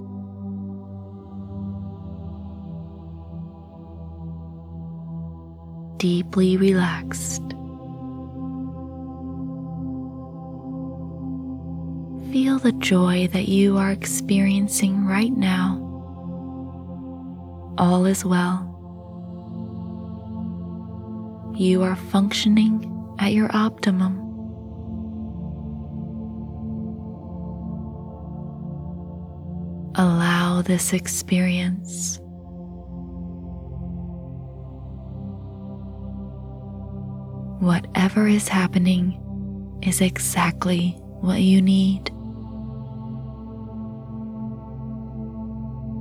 Our audio hypnosis session can help you reach a level of confidence you never thought possible by unlocking the confidence that lies within you.